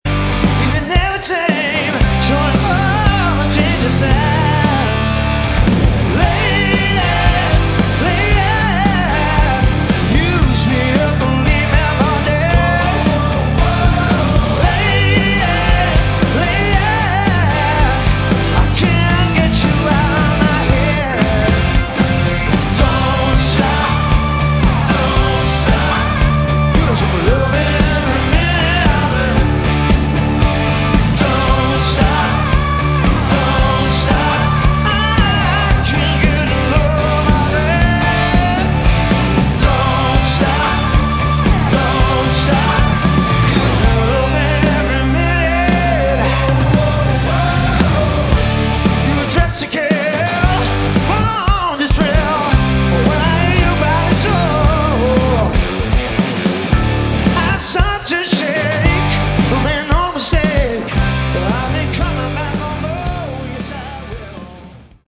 Category: Melodic Rock / AOR
Vocals, Guitar